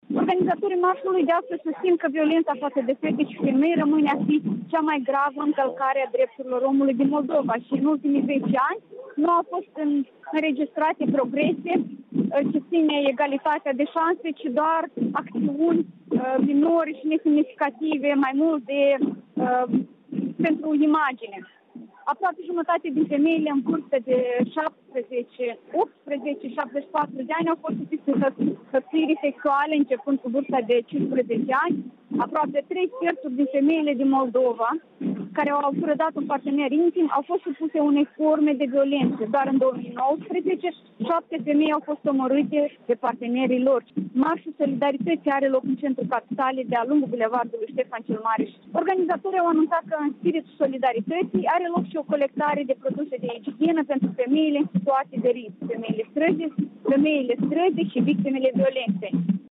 „Ratificați, nu trișați” - Marșul solidarității la Chișinău